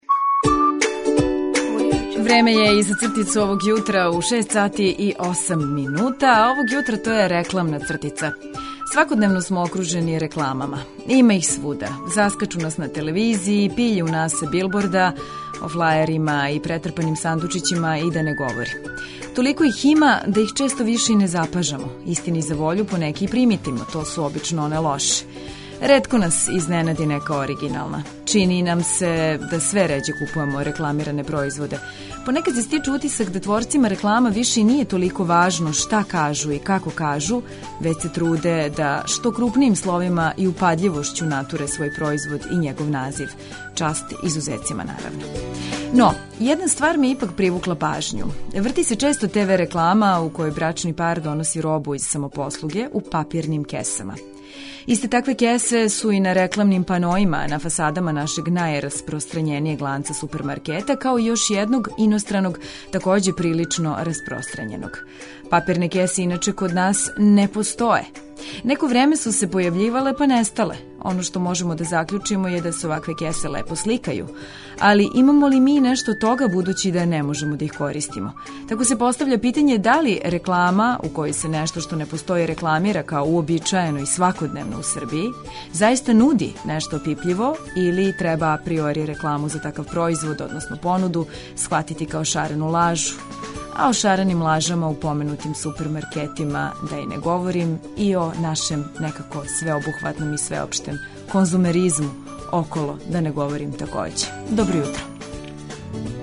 Поред вести, сервисних информација, прогнозе времена и извештаја о стању на путевима, доносимо и низ рубрика.